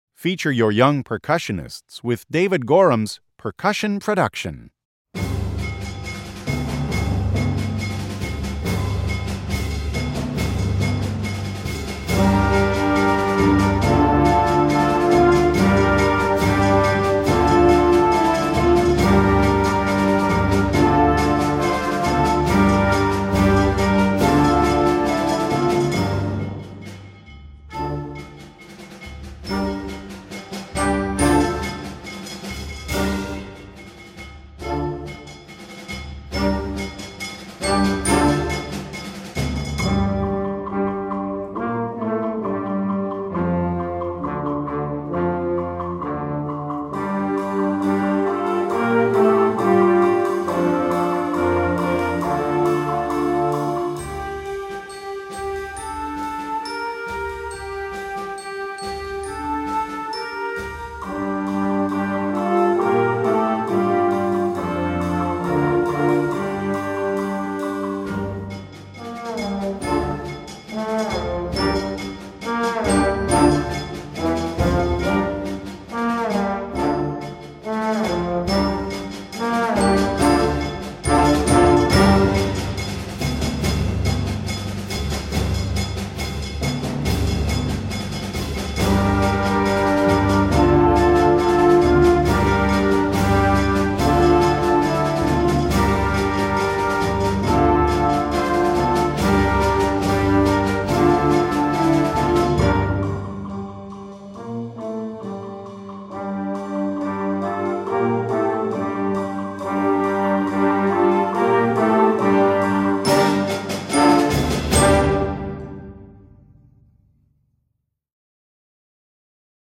Voicing: Percussion Section w/ Band